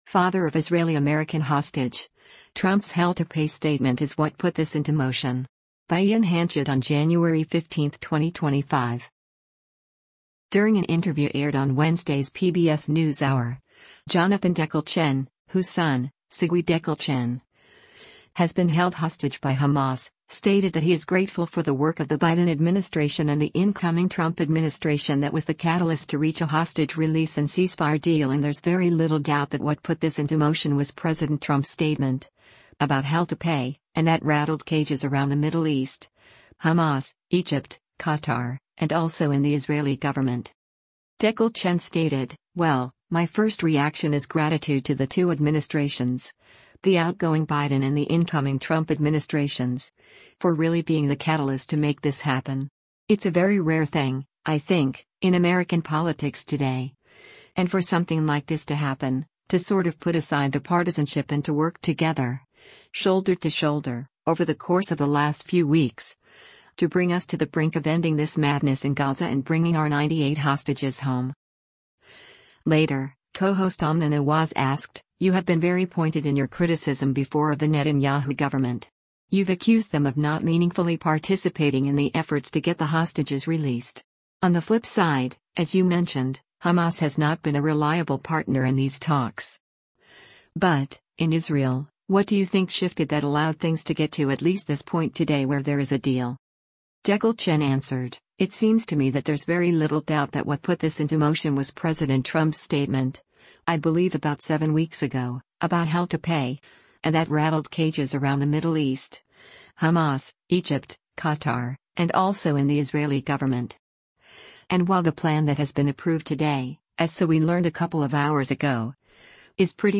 During an interview aired on Wednesday’s “PBS NewsHour